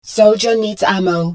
marine_needsammo2.wav